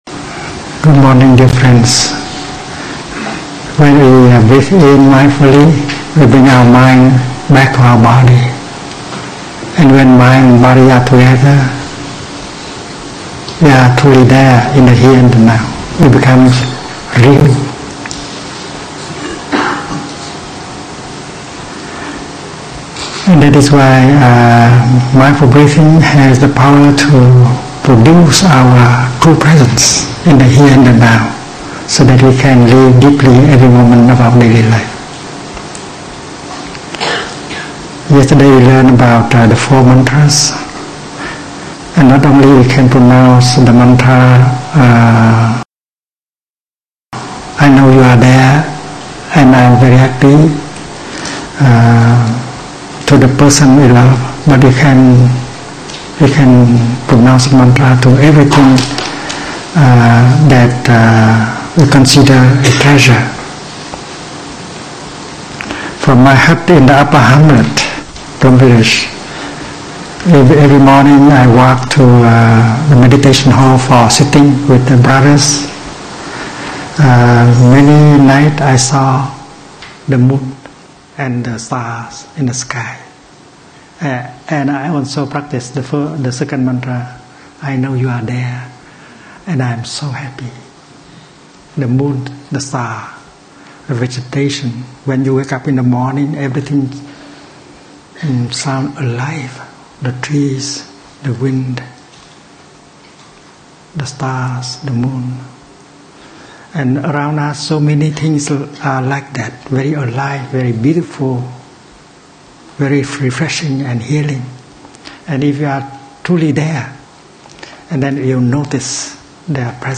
April 1, 2012. 67-minute dharma talk given at The American School in London by Thich Nhat Hanh.
The first few minutes the audio is bad but then improves. In this talk we learn about being present through mindfully eating a tangerine – it is a spiritual experience.